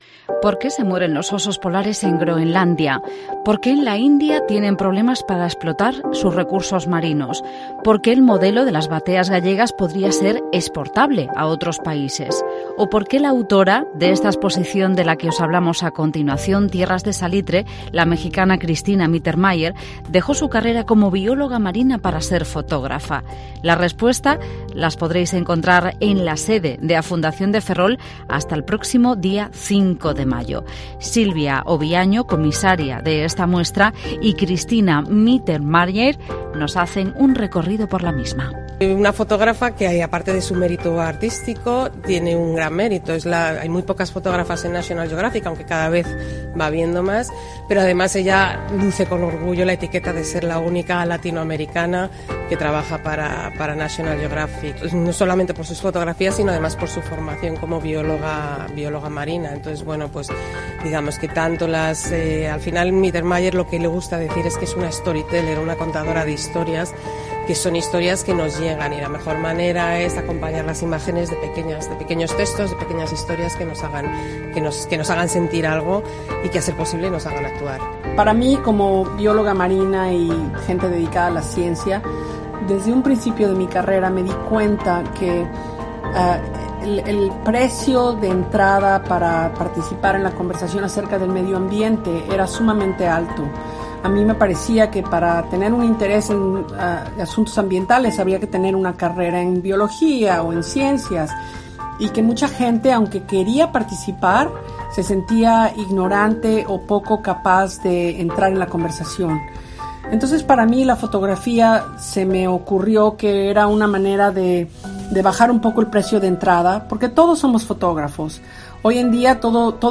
Reportaje sobre la exposición fotográfica "Tierras de salitre. Cristina Mittermeier"